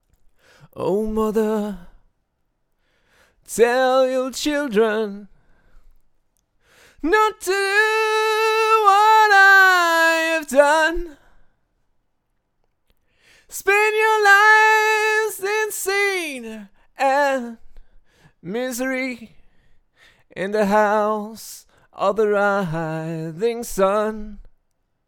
Je suis d'accord : la prise est plutôt très propre ... Il faut un peu nettoyer dans le bas (on entend des bruits dans l'extrême grave), contrôler un peu et la mettre en valeur.
HOTRS-VOIX.mp3